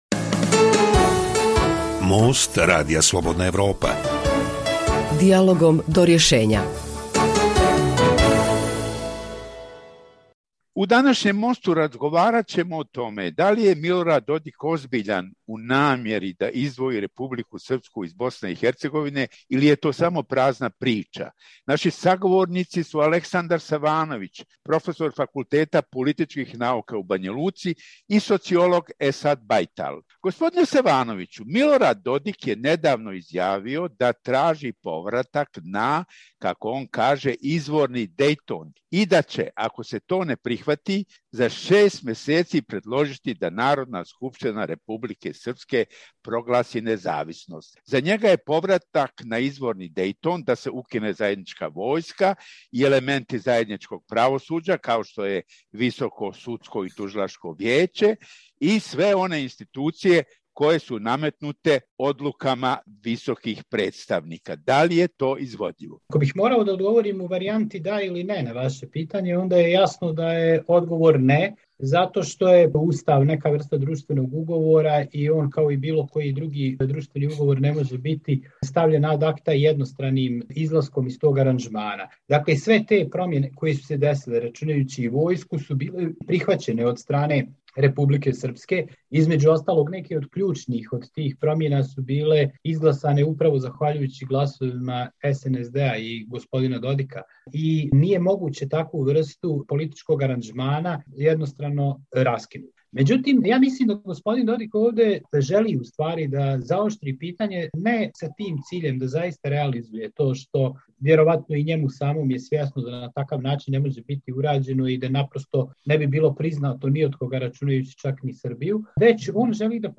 U najnovijem Mostu Radija Slobodna Evropa vođena je polemika o tome da li iza najnovijih prijetnji Milorada Dodika o secesiji Republike Srpske stoji ozbiljna namjera li je to samo prazna retorika.